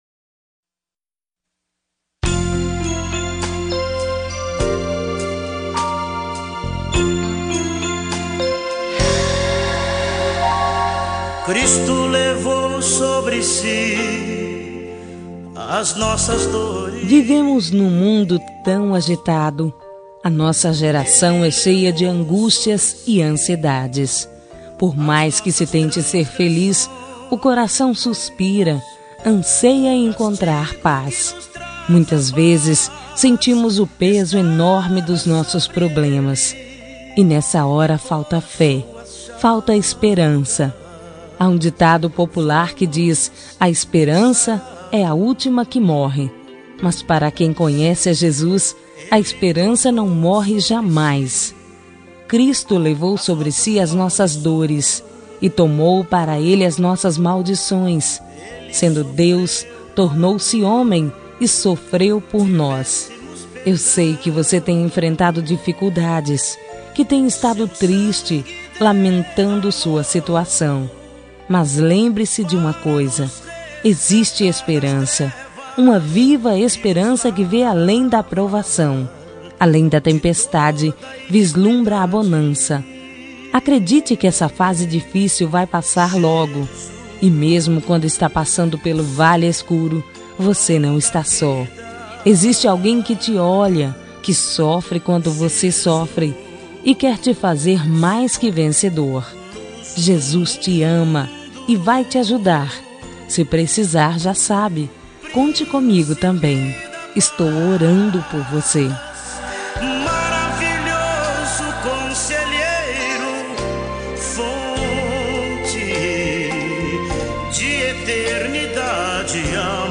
Telemensagem de Otimismo – Voz Feminina – Cód: 1760 – Linda
176-otimismo-fem-linda.m4a